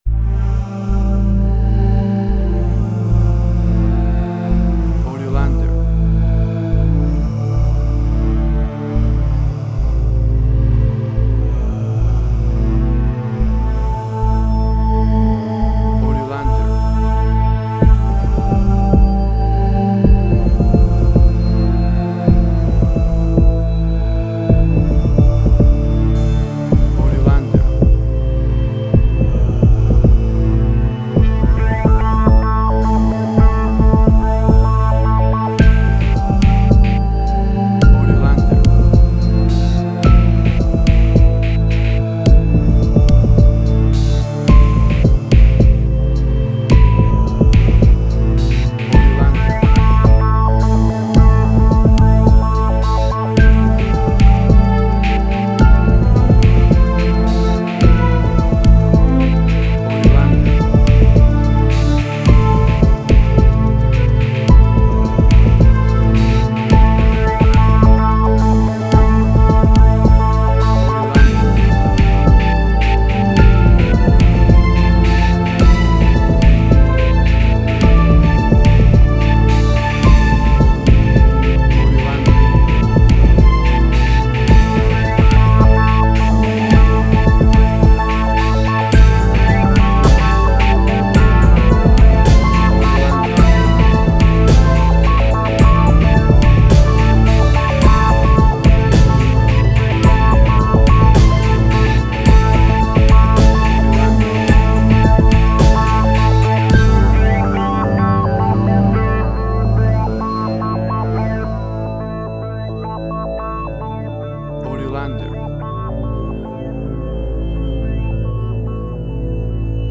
Ambient Strange&Weird.
Tempo (BPM): 55